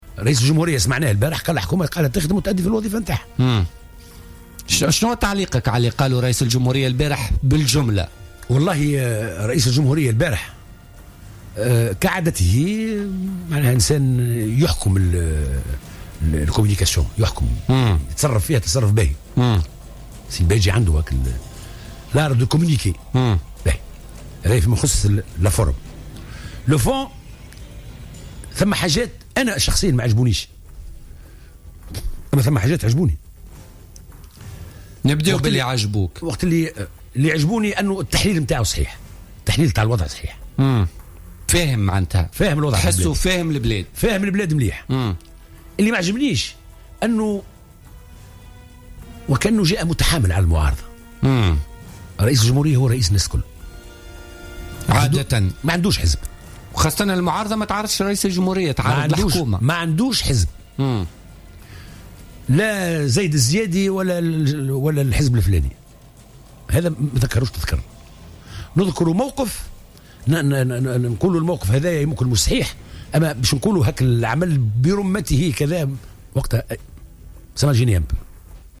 علق عبادة الكافي النائب بمجلس نواب الشعب عن كتلة الحرة وضيف برنامج بوليتكا لليوم الخميس 31 مارس 2016 على تصريحات رئيس الجمهورية التي أدلى بها صباح أمس لعدد من وسائل الإعلام والتي قال فيها إن المعارضة تمارس إرهابا فكريا على الحكومة.